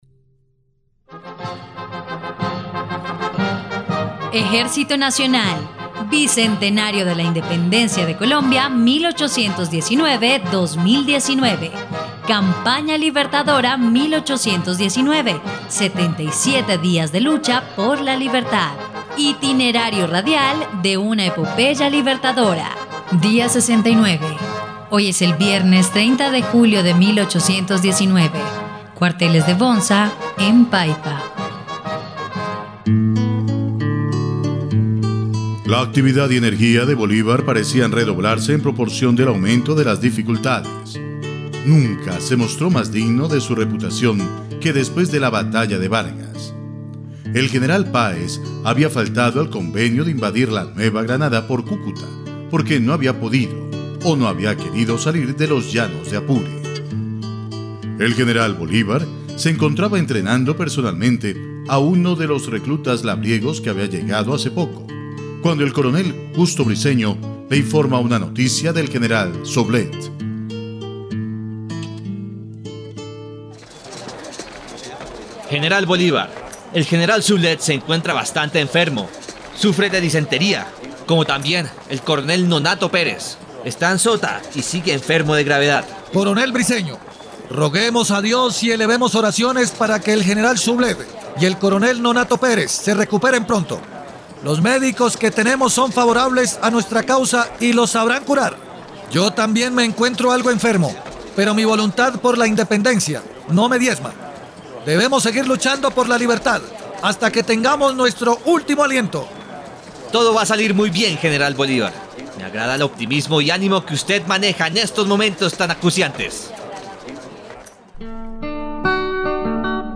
dia_68_radionovela_campana_libertadora_0.mp3